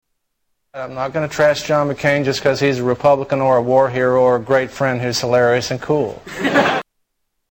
Category: Comedians   Right: Personal
Tags: Comedians Darrell Hammond Darrell Hammond Impressions SNL Television